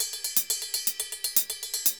Ride_Baion 120_2.wav